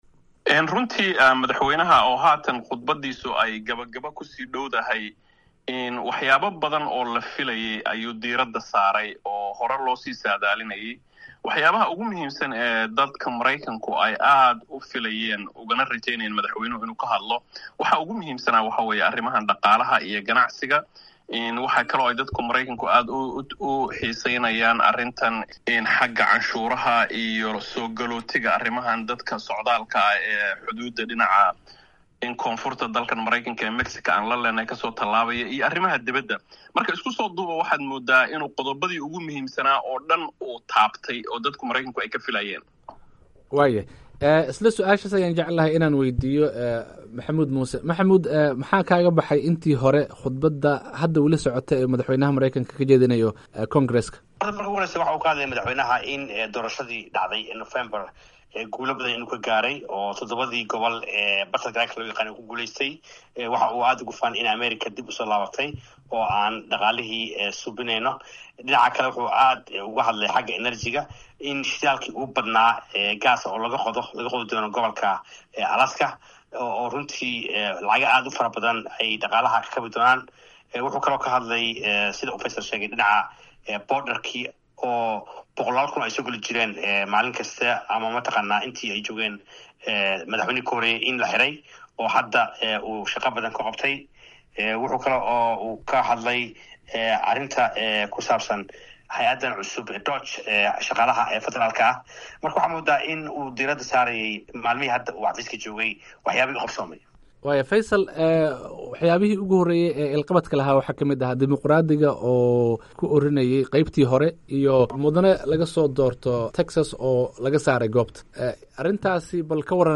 Fallanqeyn: Khudbaddii madaxweyne Trump ee Congress-ka